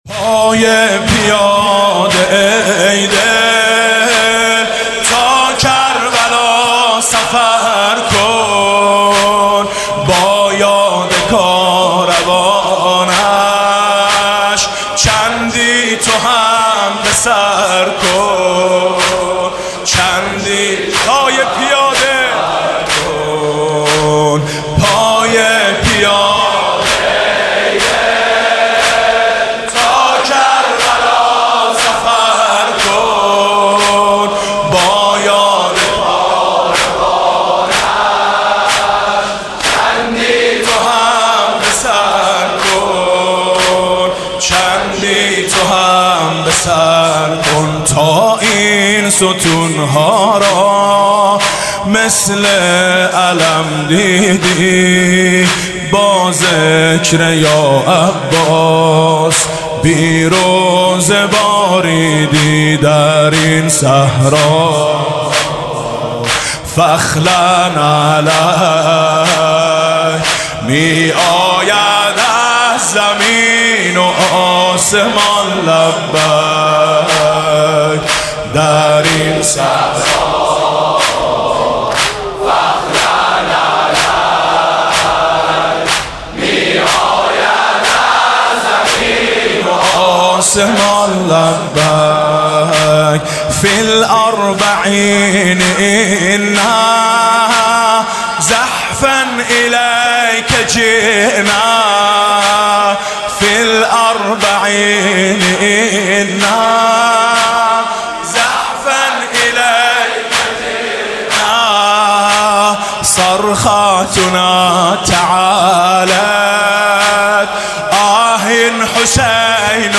7 آبان 96 - شهدای گمنام - زمینه - در این صحرا فَاخْلَعْ نَعْلَیْک